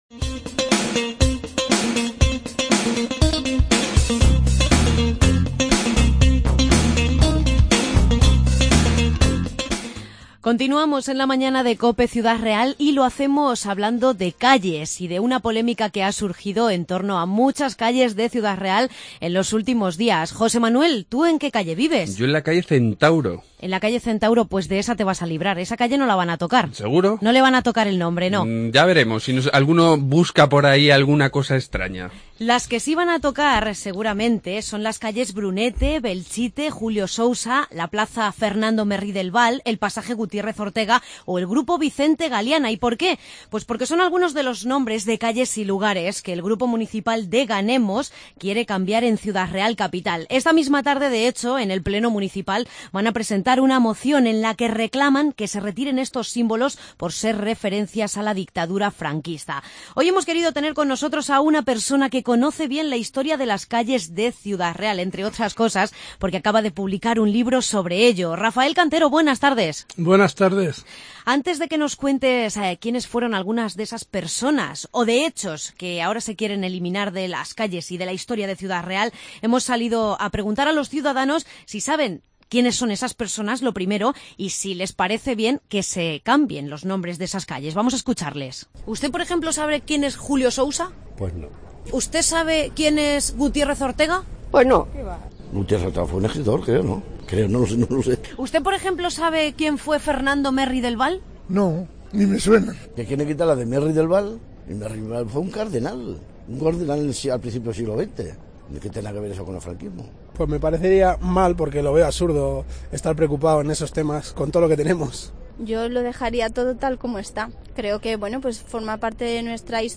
entrevista calles de Ciudad Real